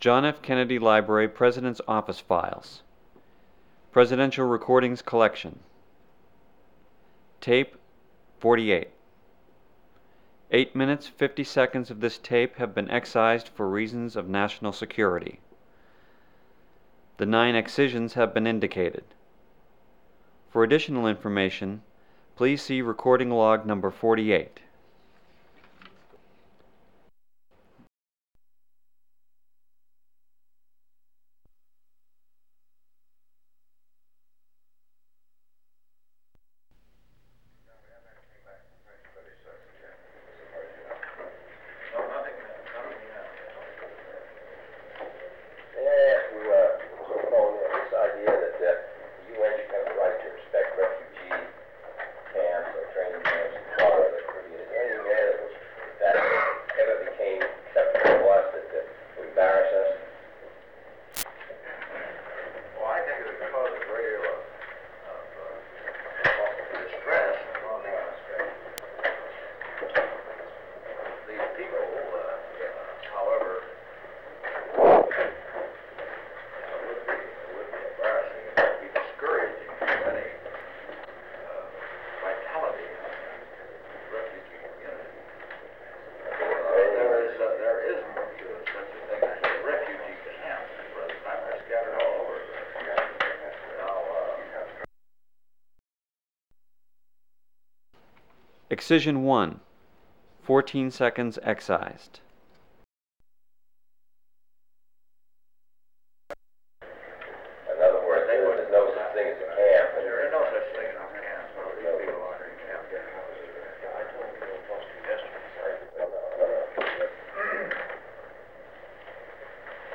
Secret White House Tapes | John F. Kennedy Presidency Executive Committee Meeting of the National Security Council Rewind 10 seconds Play/Pause Fast-forward 10 seconds 0:00 Download audio Previous Meetings: Tape 121/A57.